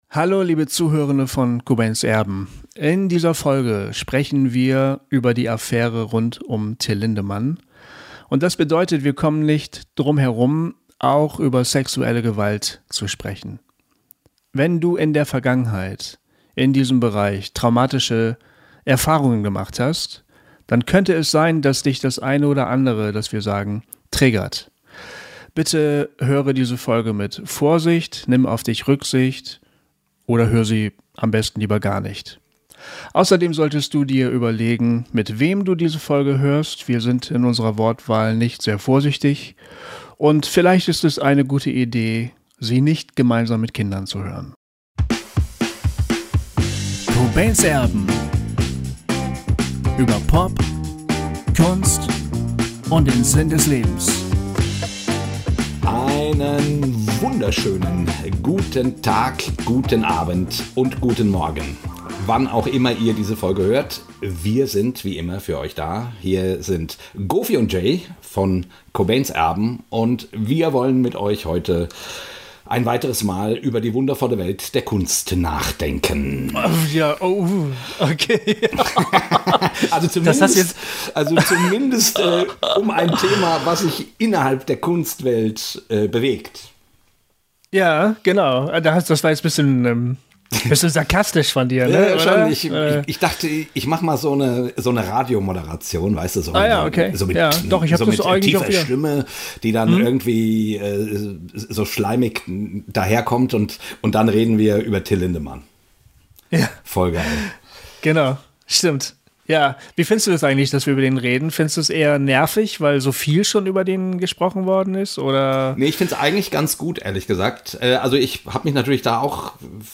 Und welche Vorstellung von Männlichkeit ist für Auswüchse dieser Art verantwortlich? All diesen Fragen gehen wir in unserem letzten Talk vor der Sommerpause nach.